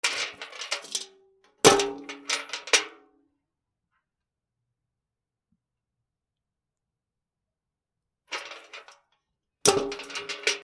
水の入ったバケツ
『チャ カラン』